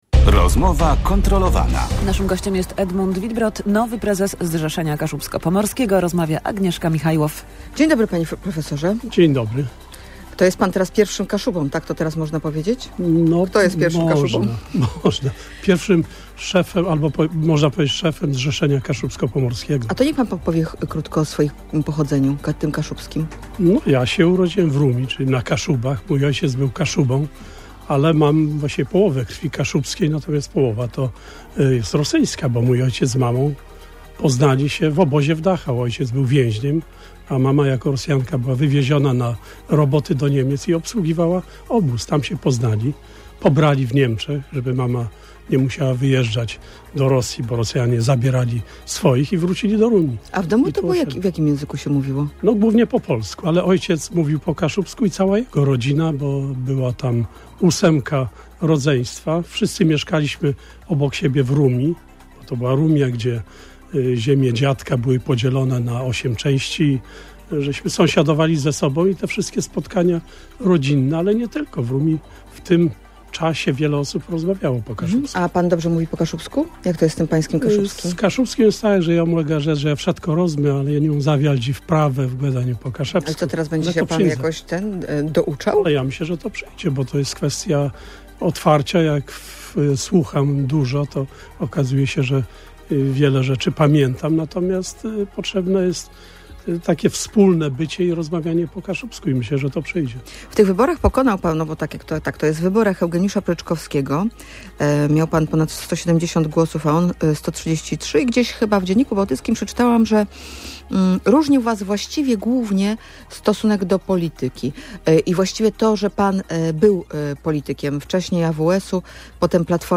Jednak, jak zapewnia, „słucha i zapamiętuje”, więc dzięki rozmowom po kaszubsku, w końcu się języka nauczy. Nowy prezes Zrzeszenia Kaszubsko-Pomorskiego Edmund Wittbrodt był gościem Radia Gdańsk.
Nowy prezes Zrzeszenia Kaszubsko-Pomorskiego był gościem Rozmowy Kontrolowanej.